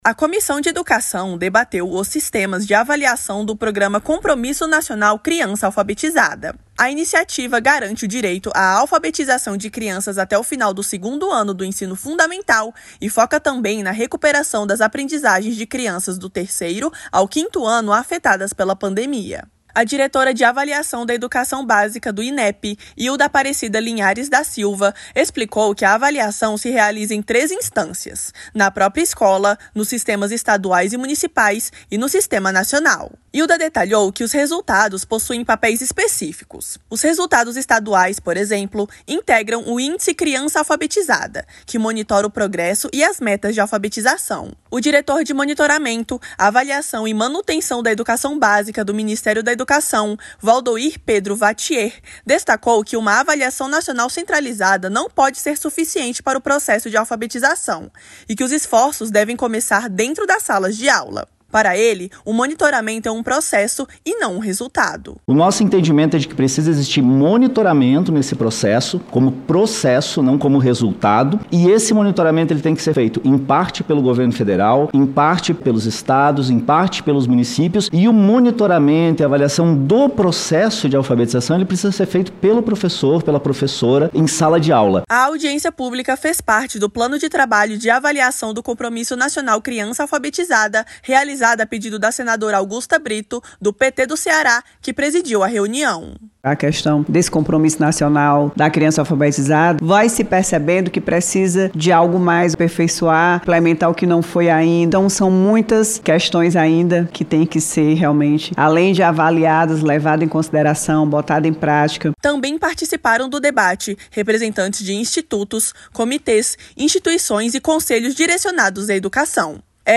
A Comissão de Educação (CE) debateu em audiência pública os sistemas de avaliação do programa Compromisso Nacional Criança Alfabetizada. A iniciativa federal busca garantir o direito à alfabetização de crianças até o 2º ano do ensino fundamental e recuperar o aprendizado afetado pela pandemia. Foram ouvidos representantes do INEP, MEC e instituições da sociedade civil.